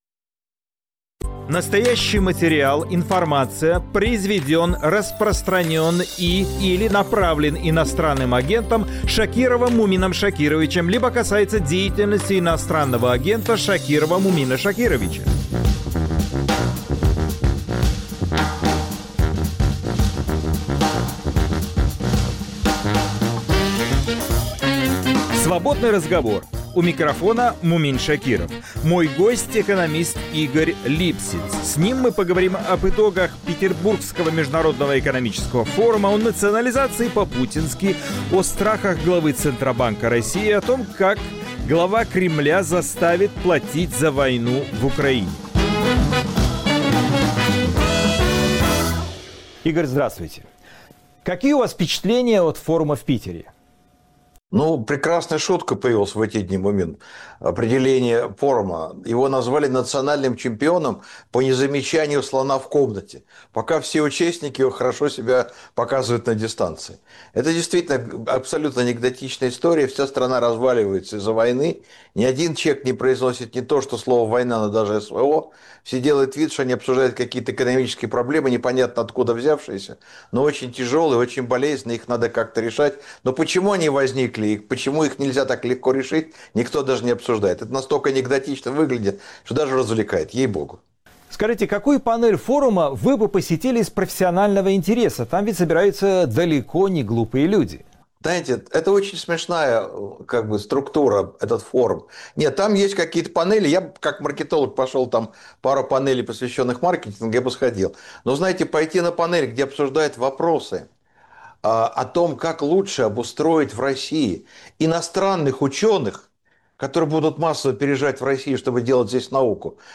Свободный разговор c экономистом Игорем Липсицем